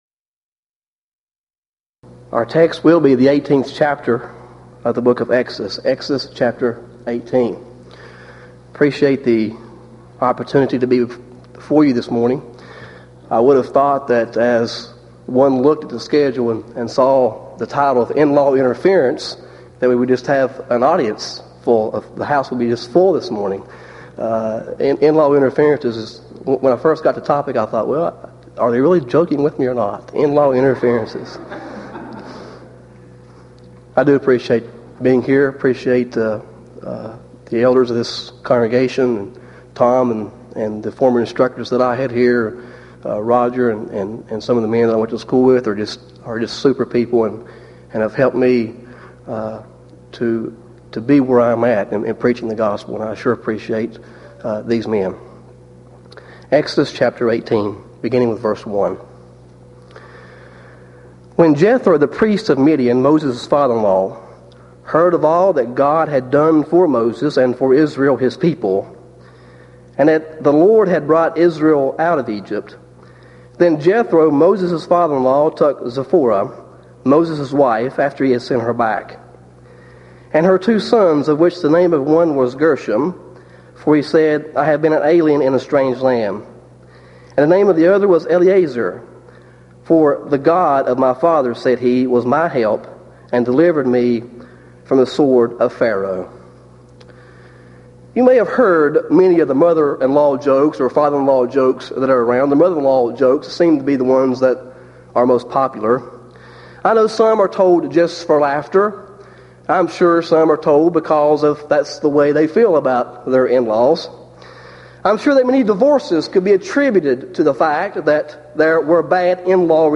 Event: 1997 East Tennessee School of Preaching Lectures Theme/Title: Studies In The Book of Exodus